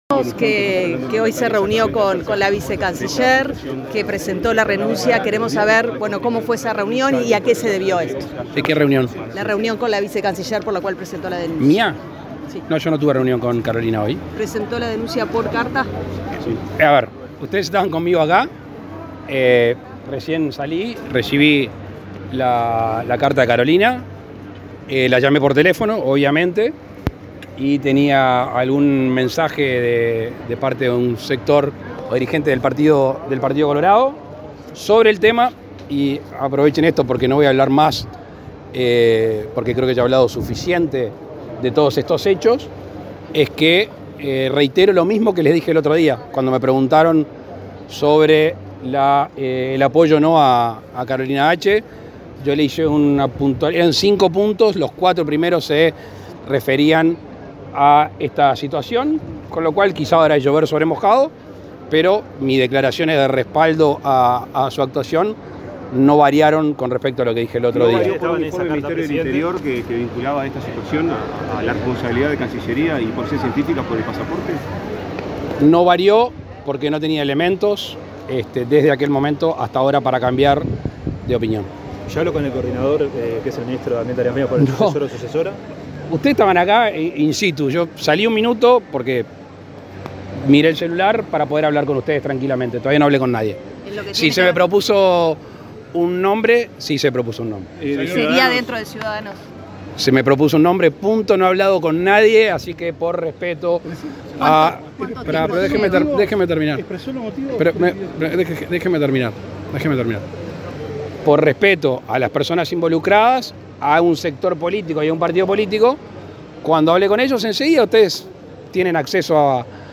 Declaraciones a la prensa del presidente de la República, Luis Lacalle Pou
Declaraciones a la prensa del presidente de la República, Luis Lacalle Pou 19/12/2022 Compartir Facebook X Copiar enlace WhatsApp LinkedIn Tras participar en la inauguración del aeropuerto internacional de Carmelo, este 19 de diciembre, el presidente de la República, Luis Lacalle Pou, realizó declaraciones a la prensa.